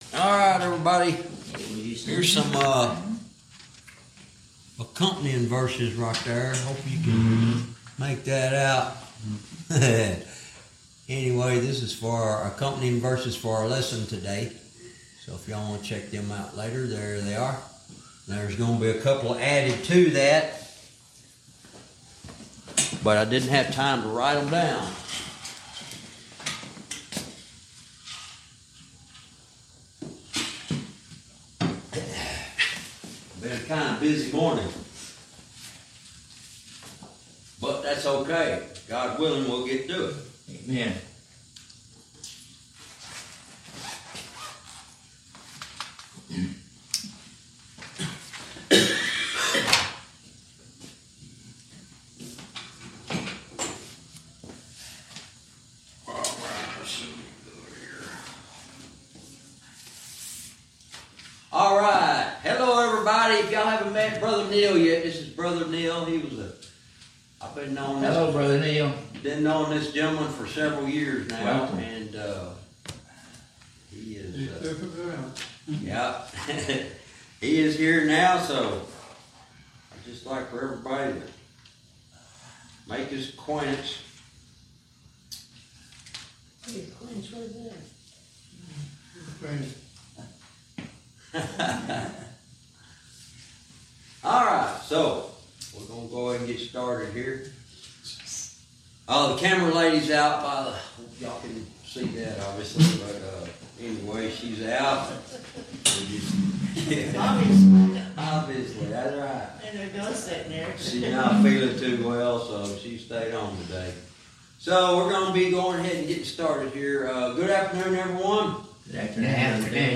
Verse by verse teaching - Jude lesson 96 verse 21